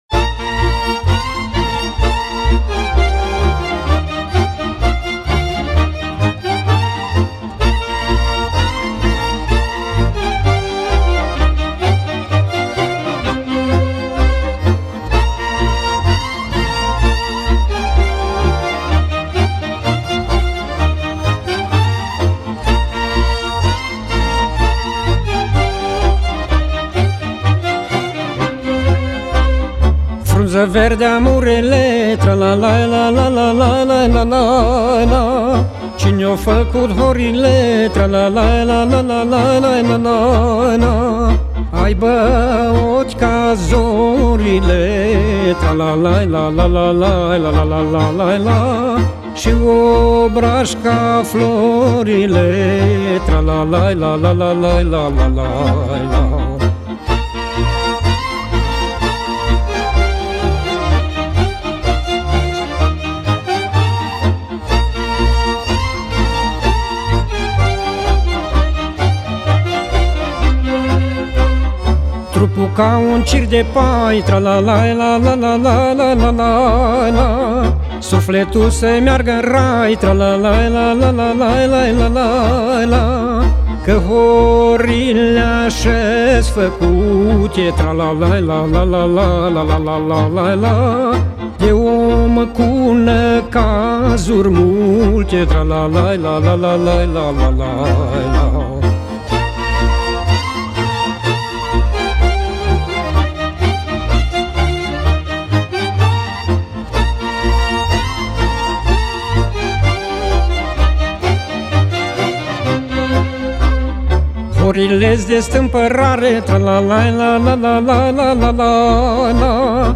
Acompaniamentul cântecelor de pe acest compact disc a fost asigurat de către Orchestra profesionistă